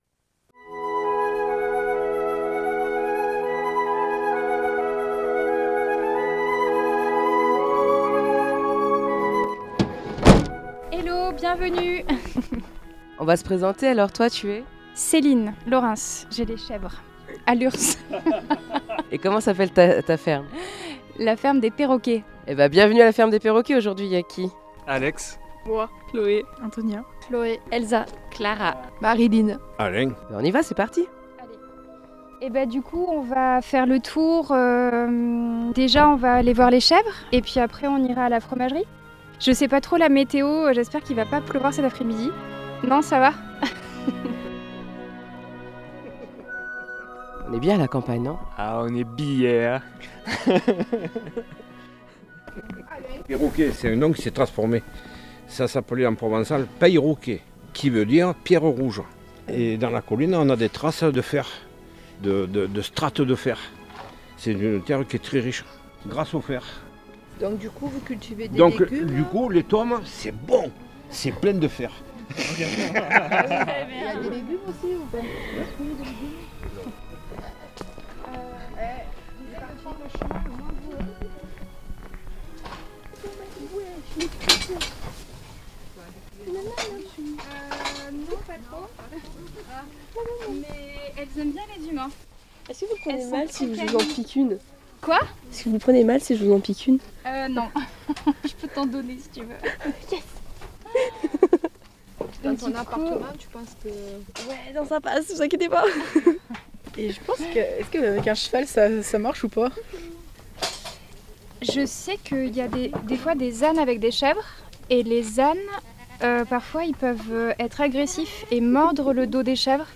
Nous l'avons suivie depuis la traite des chèvres jusqu'à leur sortie dans la colline en passant par la fromagerie où se fabriquent de délicieux fromages de chèvres!
Le reportage La Ferme des Perroquets